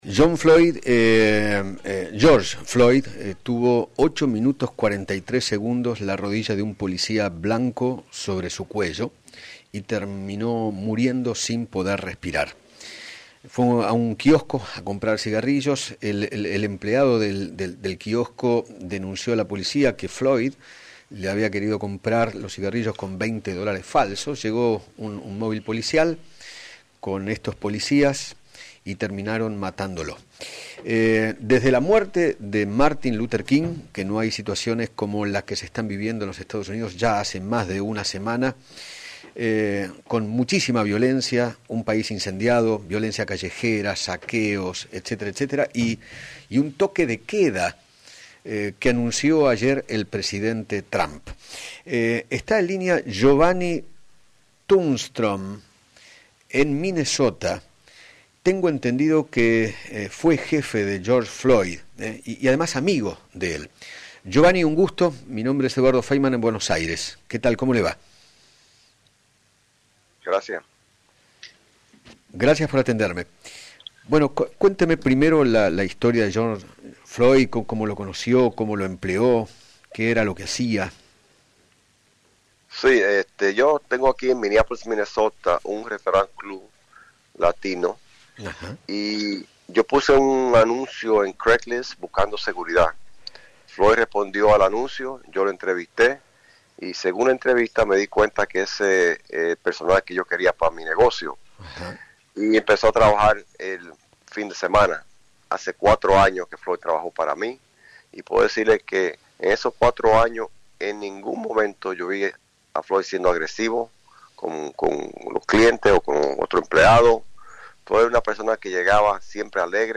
dialogó con Eduardo Feinmann sobre la personalidad de George y la relación que tenía con él.